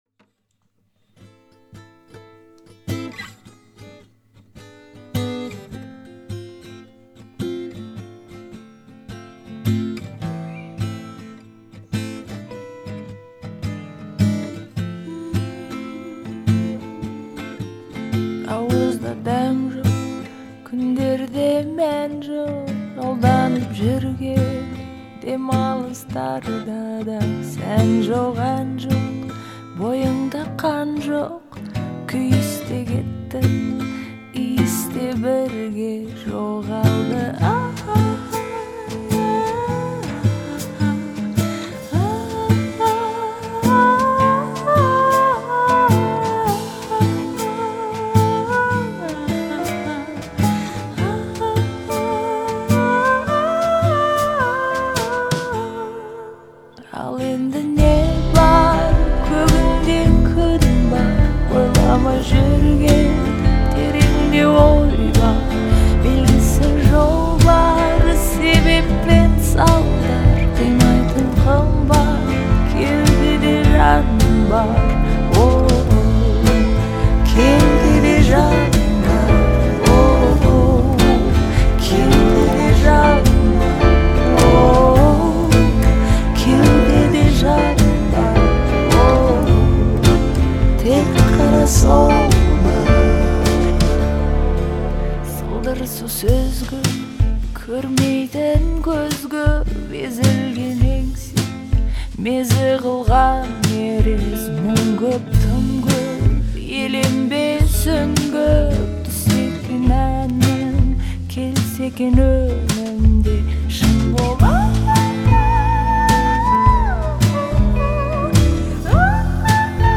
это яркая и мелодичная песня в жанре поп